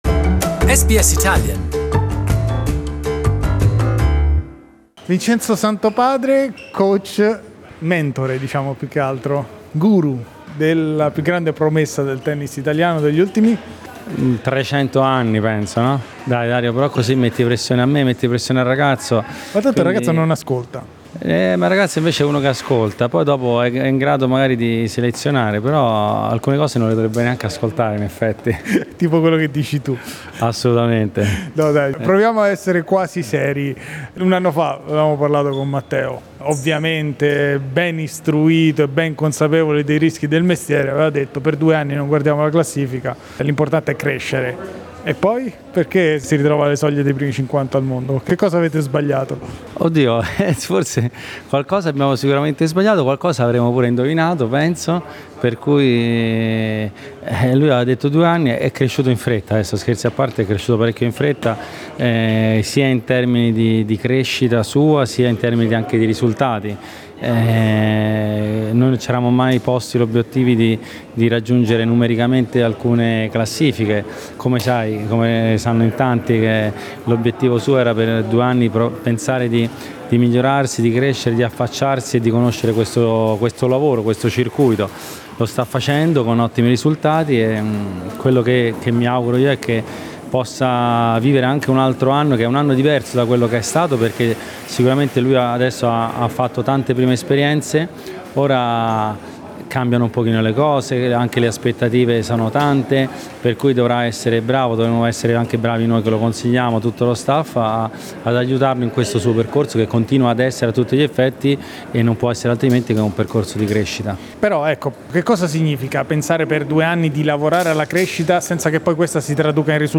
p.s. la definizione non è nostra ma è di Berrettini, che si è inserito nell'intervista.